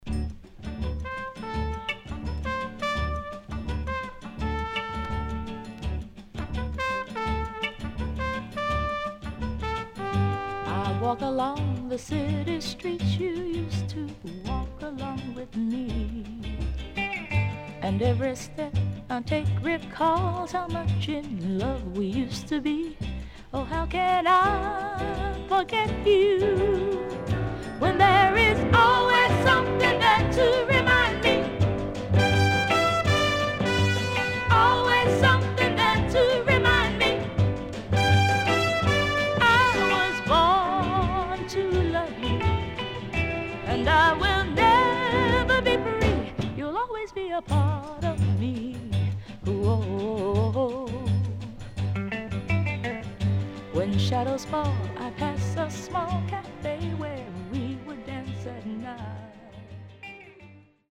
HOME > SOUL / OTHERS
Side A:序盤少しプチノイズ入ります。